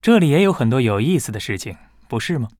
文件 文件历史 文件用途 全域文件用途 Bhan_amb_03.ogg （Ogg Vorbis声音文件，长度2.6秒，109 kbps，文件大小：34 KB） 源地址:游戏中的语音 文件历史 点击某个日期/时间查看对应时刻的文件。